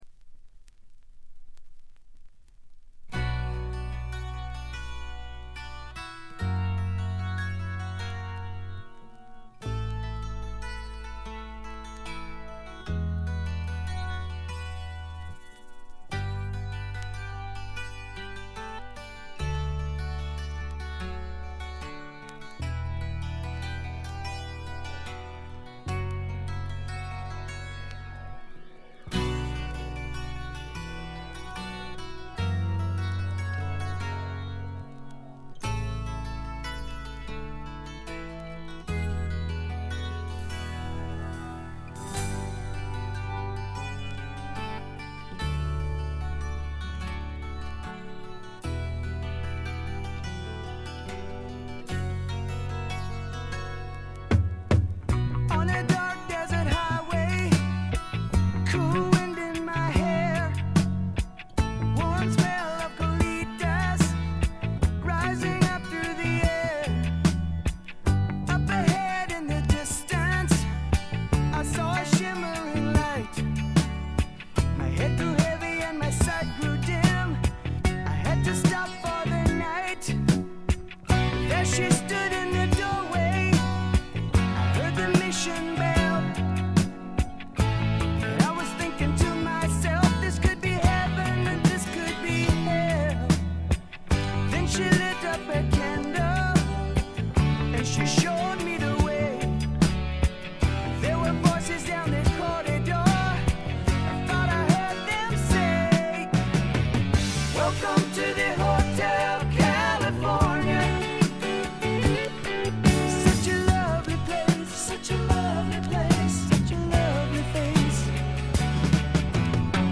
静音部で軽微なバックグラウンドノイズ、チリプチ。
試聴曲は現品からの取り込み音源です。